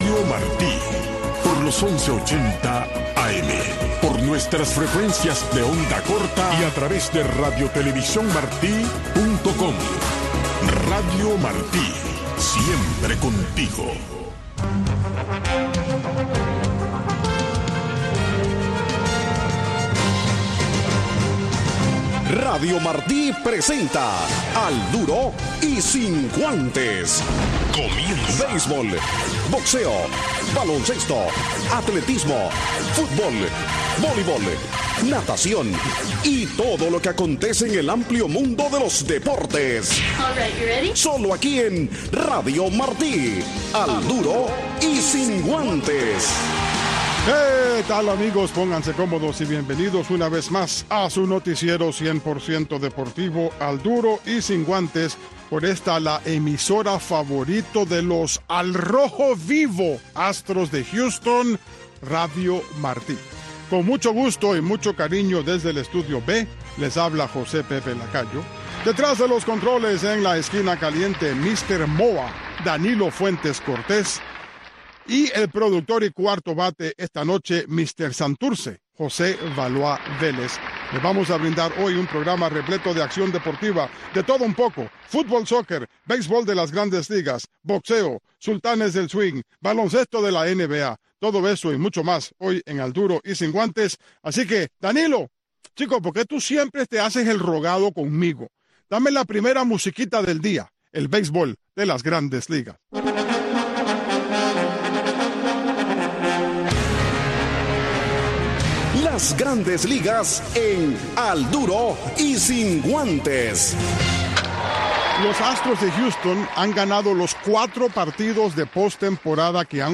Noticiero Deportivo